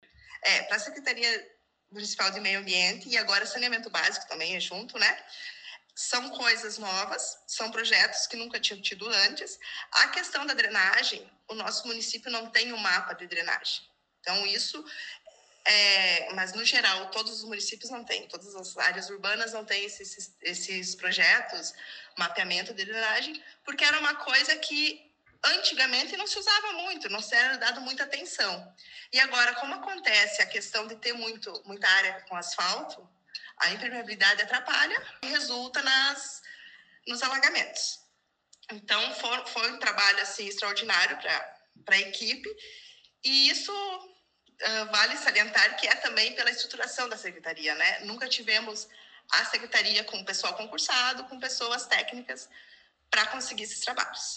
Secretária Municipal do Meio Ambiente e Saneamento Básico concedeu entrevista